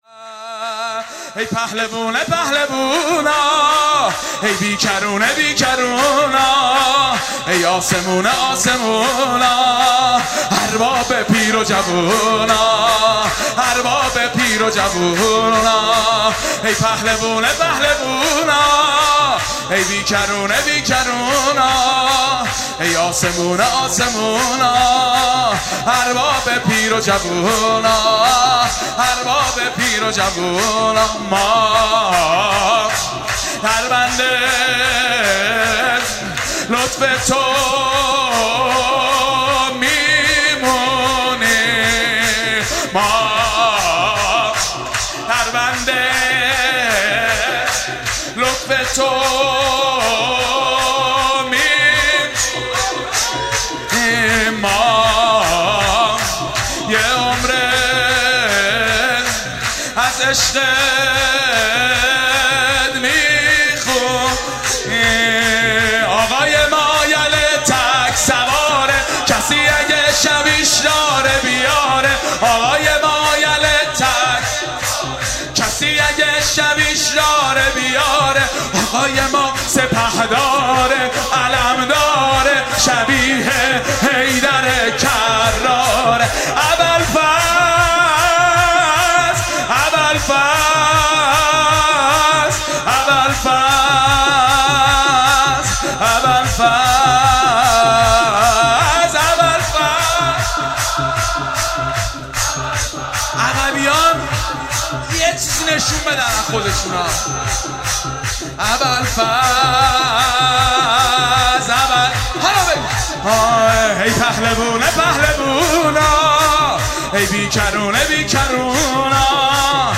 شب میلاد حضرت عباس(ع)/هیأت عبدالله الحسن(ع)
سرود
مدح